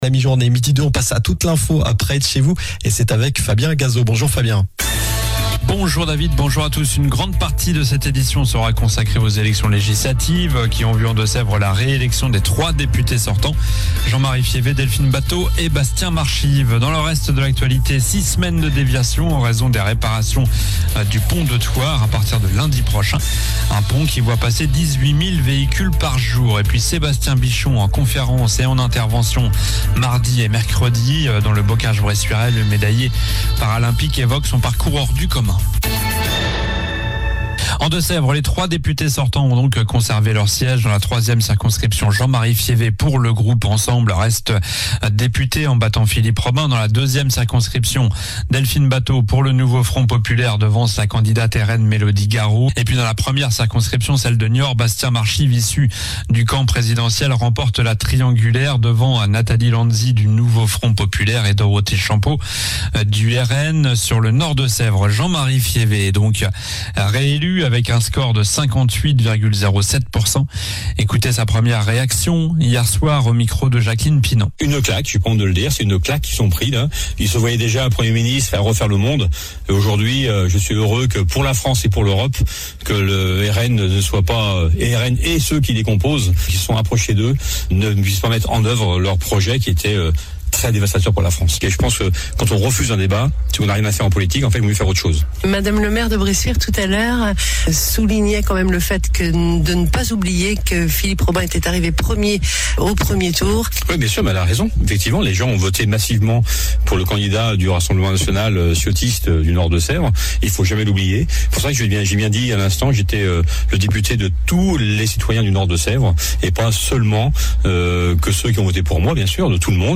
Journal du lundi 08 juillet (midi)